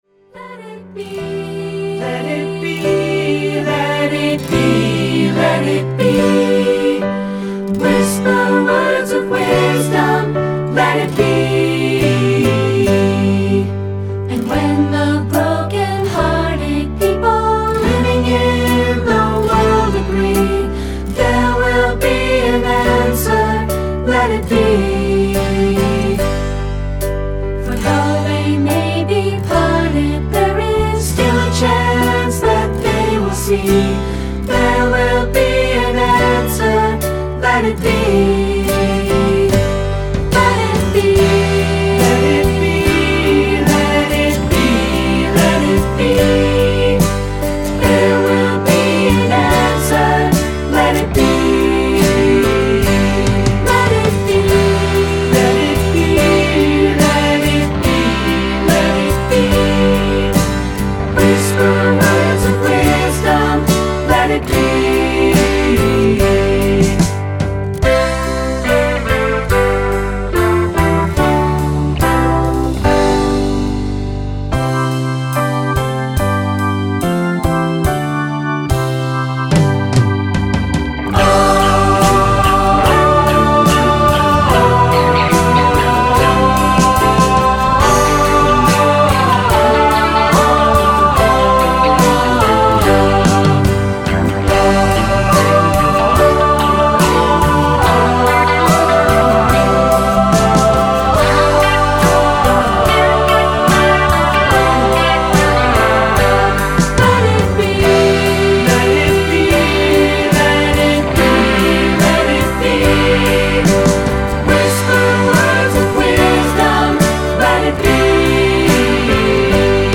Choral 50's and 60's Pop